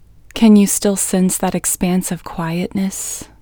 QUIETNESS Female English 19